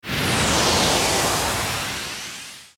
soceress_charge_start.ogg